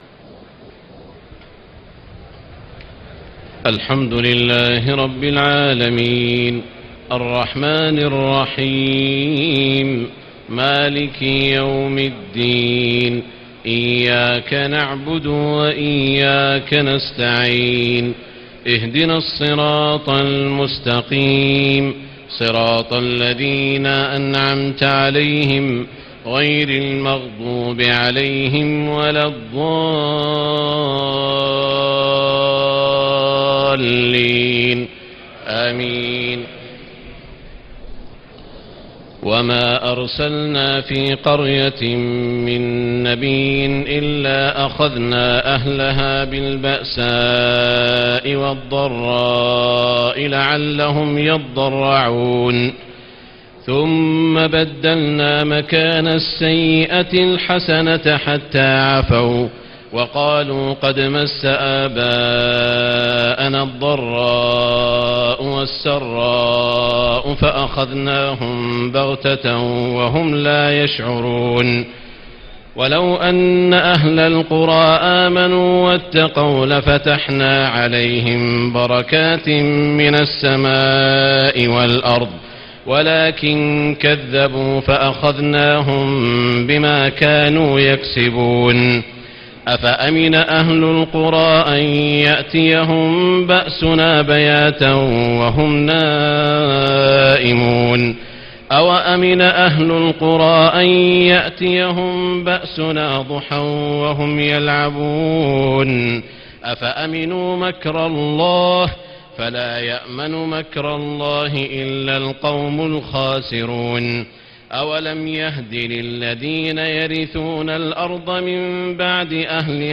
تهجد ليلة 29 رمضان 1436هـ من سورة الأعراف (94-188) Tahajjud 29 st night Ramadan 1436H from Surah Al-A’raf > تراويح الحرم المكي عام 1436 🕋 > التراويح - تلاوات الحرمين